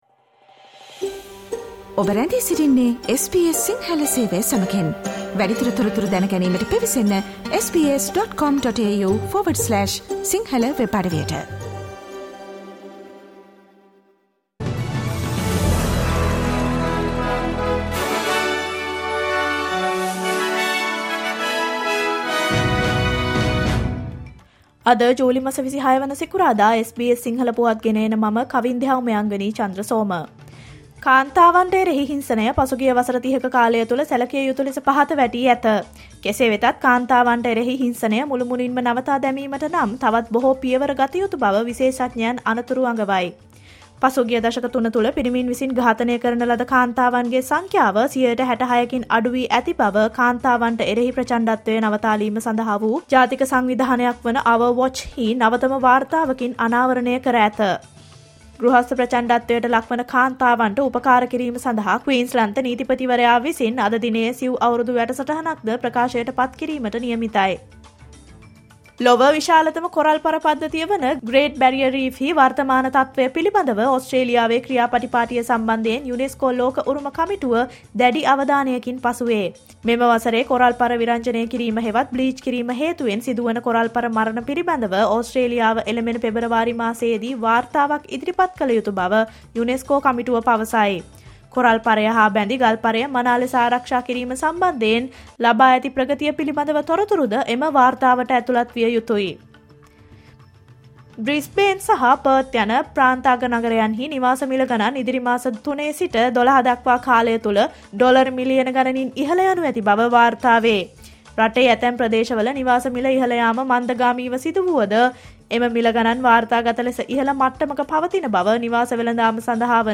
Australia news in Sinhala, foreign and sports news in brief.